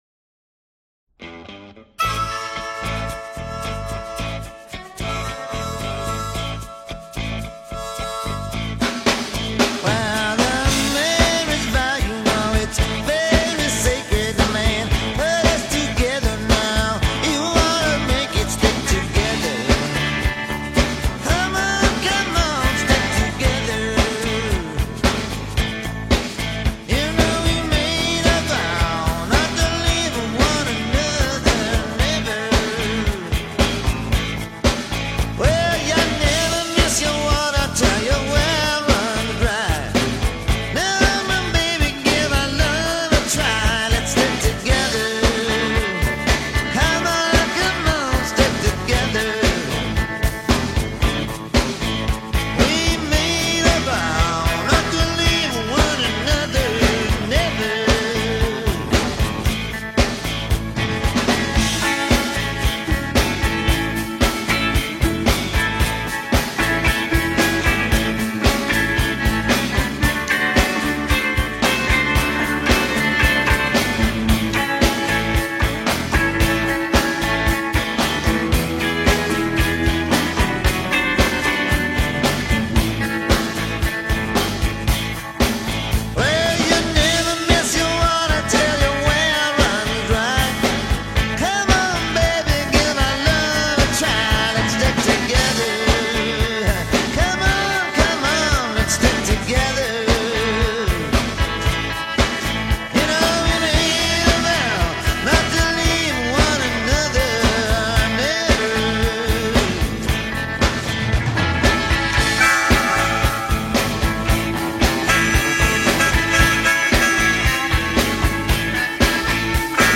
Folk Rock, Blues, Rock